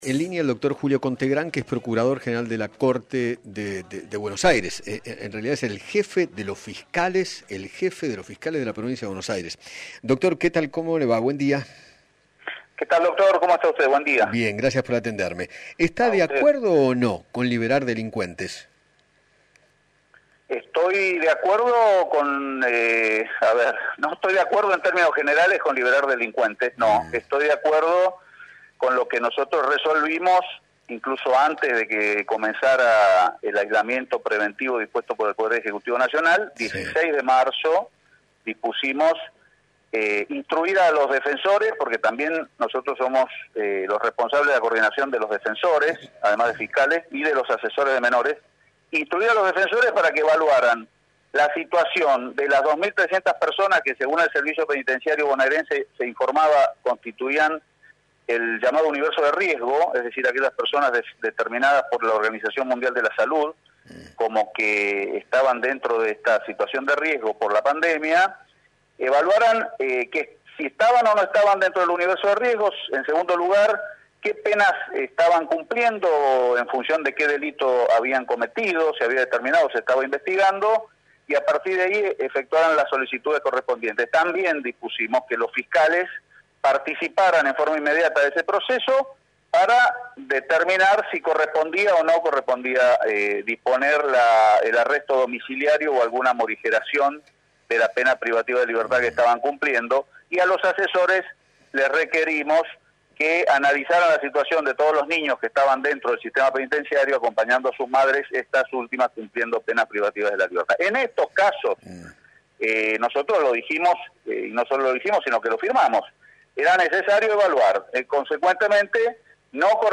Julio Conte Grand, Procurador General de la Corte bonaerense, dialogó con Eduardo Feinmann sobre el fallo de la Cámara de Casación que recomienda la excarcelación de detenidos considerados de riesgo sanitario.